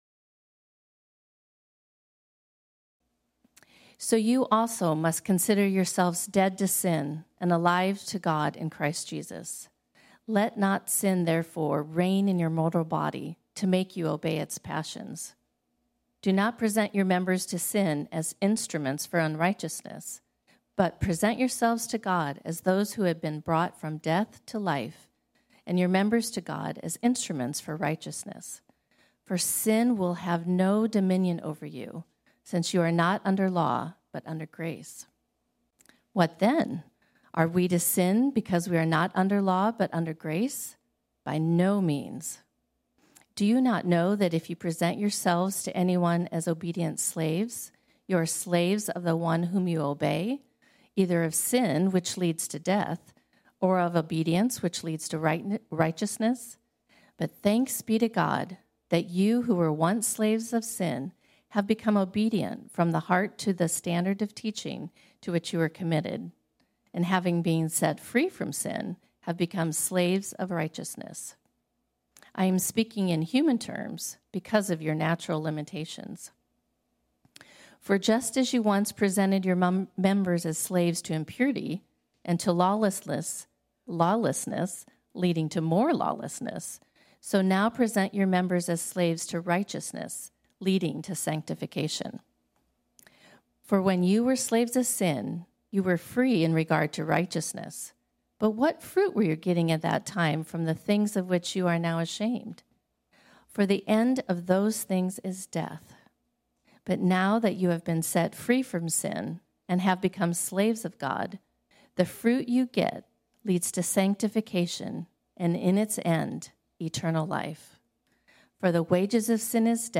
This sermon was originally preached on Sunday, April 11, 2021.